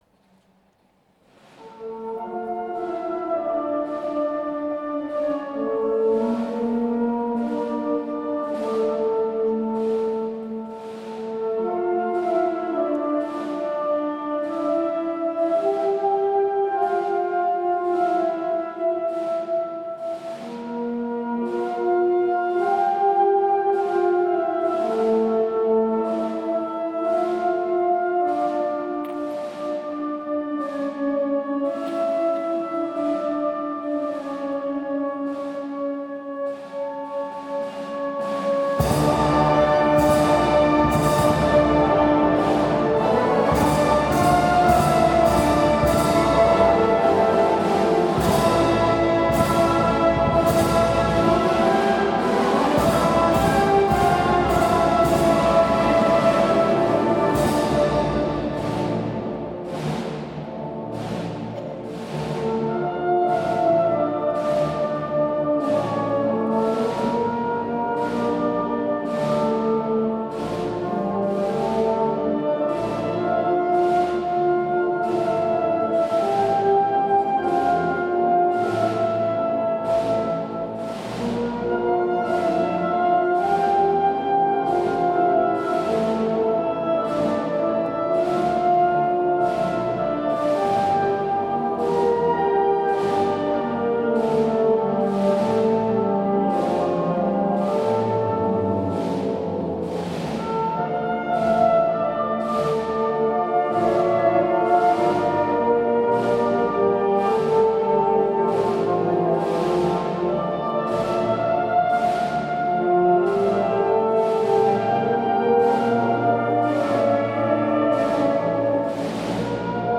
Marcha de Procesión Virgen de la Esperanza, Madre del Rescate